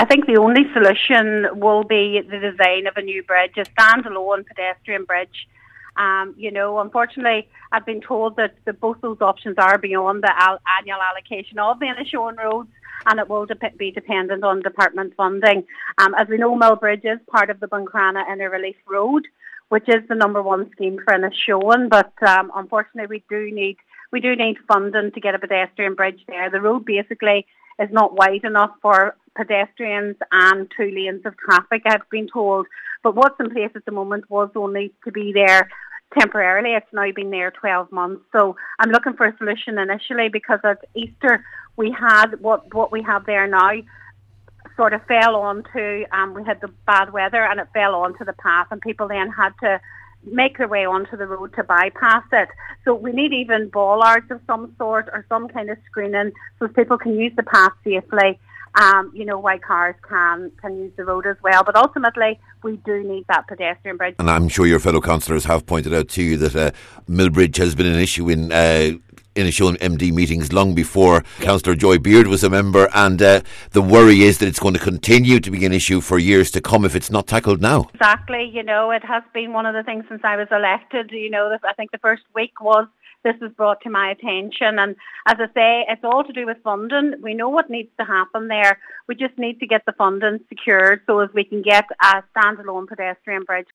Cllr Beard says the bridge is on the long term Buncrana Inner Relief Road, but pedestrian safety concerns can’t wait: